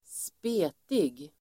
Ladda ner uttalet
spetig adjektiv (om person), skinny [of an individual]Uttal: [²sp'e:tig] Böjningar: spetigt, spetigaDefinition: mager